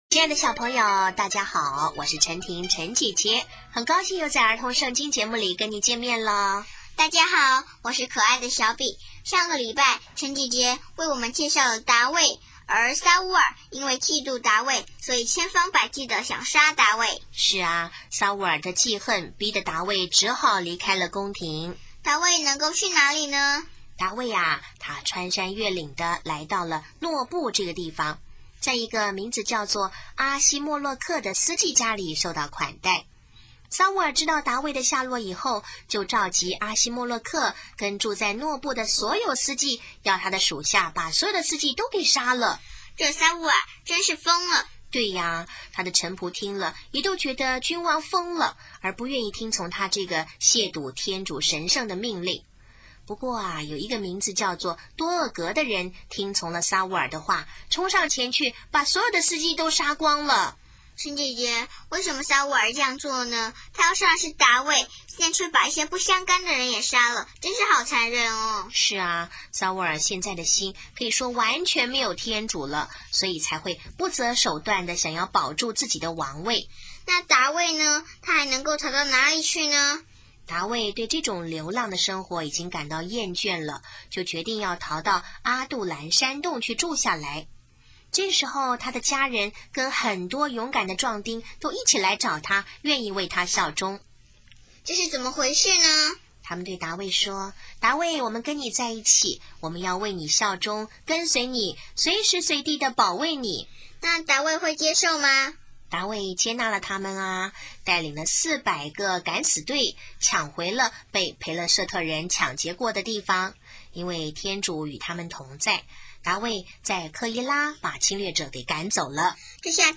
【儿童圣经故事】25|达味(二)悲歌